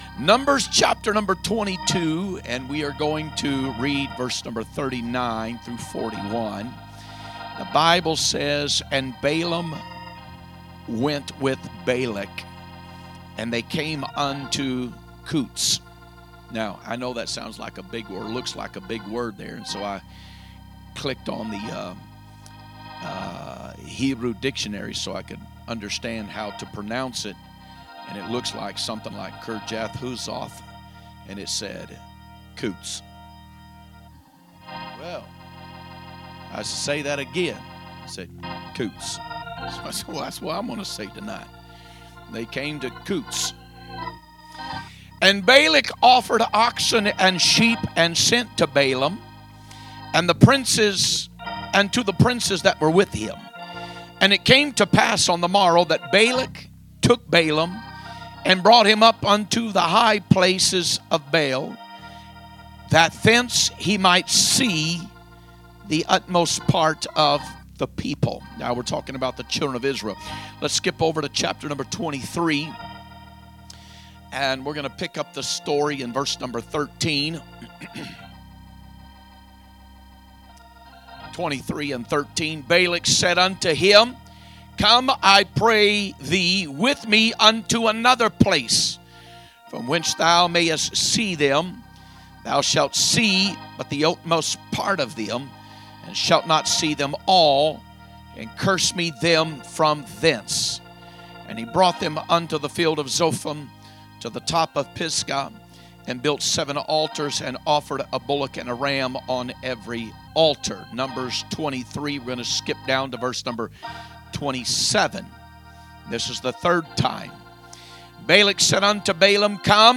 Wednesday Message